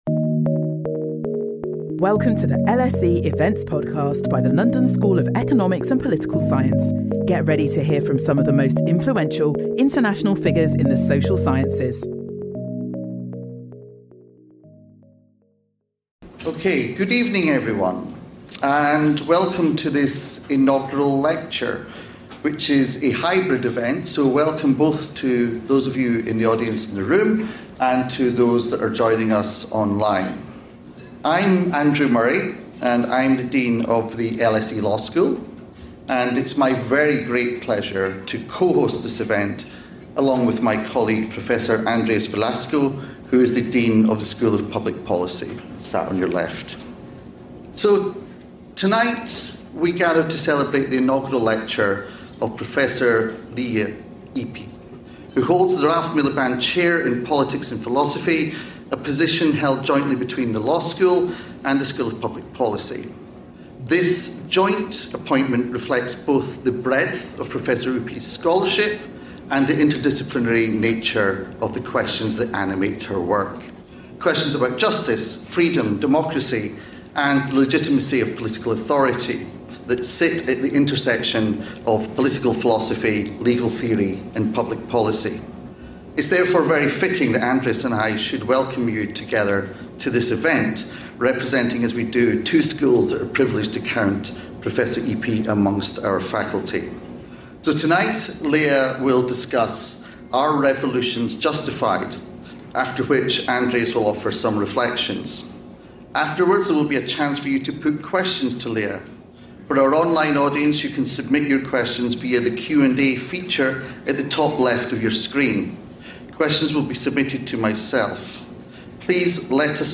In this lecture Lea Ypi revisits their arguments and offers an alternative that cuts across the divide. She examines revolution not in relation to the justice demanded by specific agents but grounded on a philosophical theory of history that focuses on collective progress.